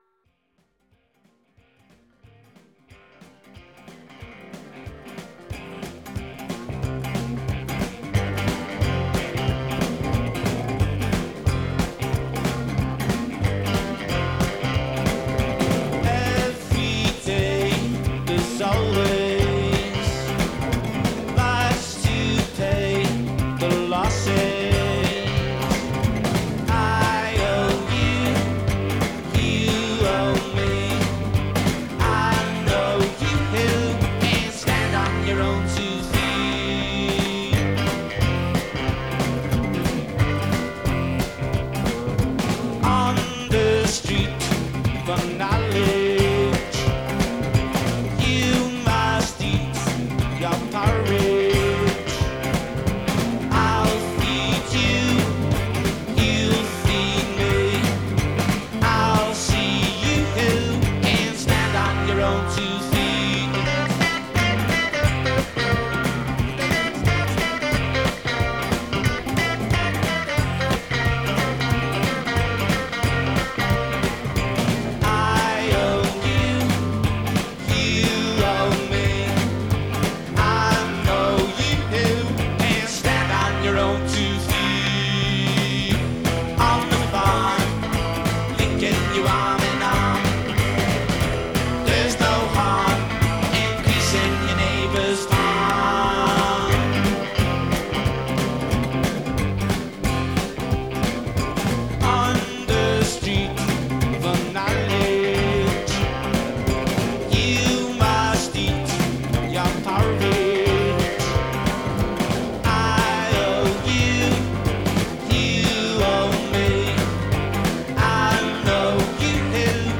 the early Beatles sound